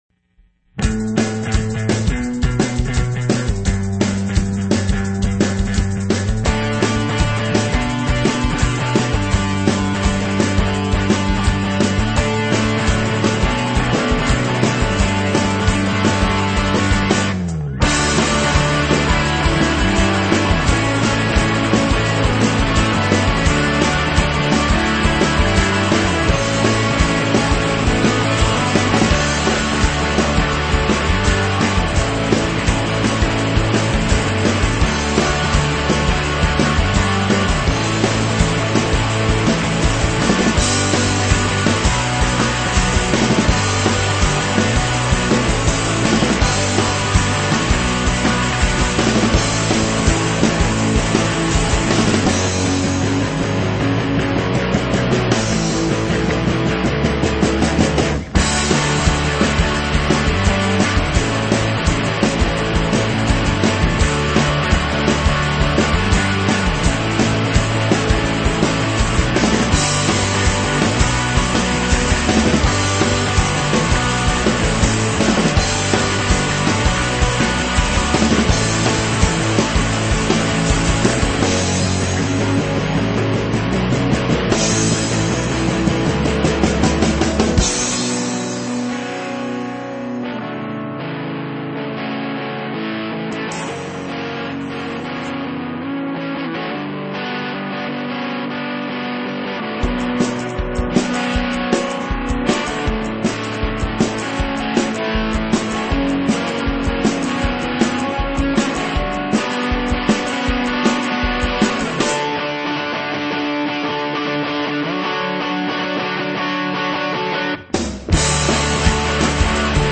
metal
rock
hard rock
punk
high energy rock and roll